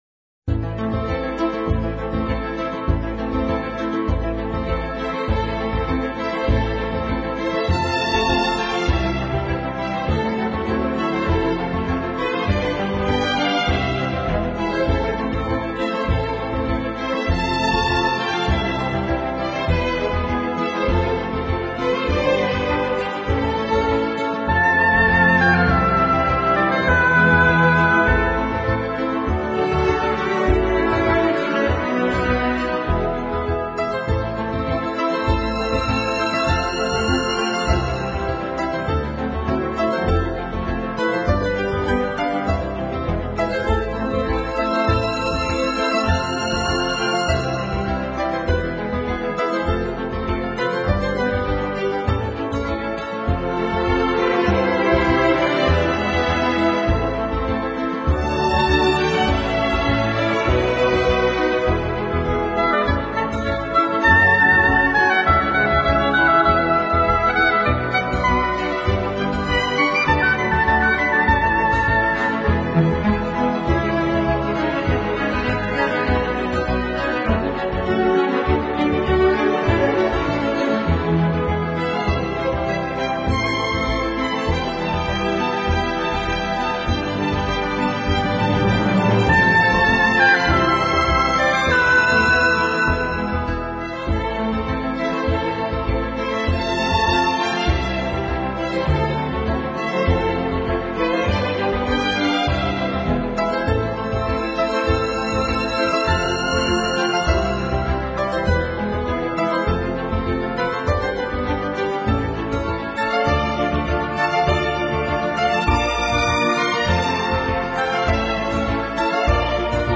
音乐风格：其他|古典|(Neo Classical，室内乐)